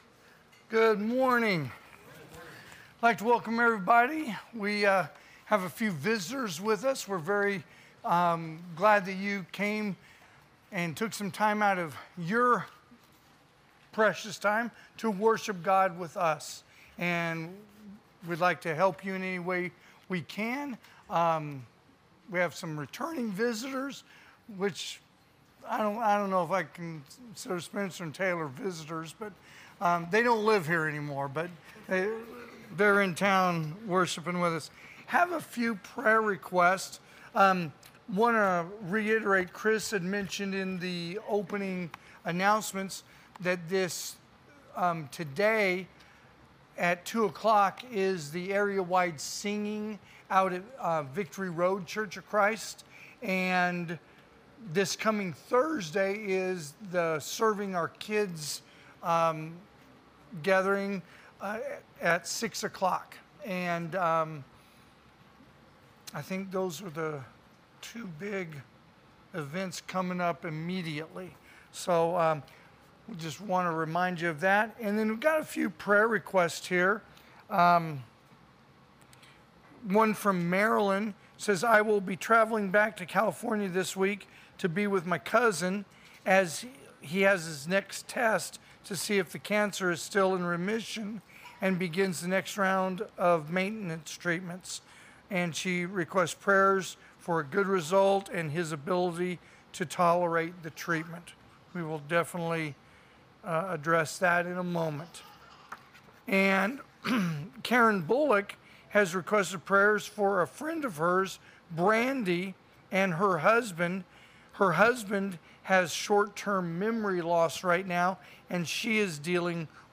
2024 (AM Worship) “Black Friday”
Sermons